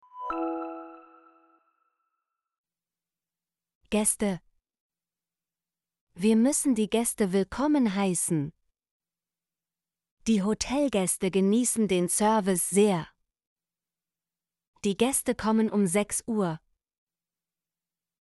gäste - Example Sentences & Pronunciation, German Frequency List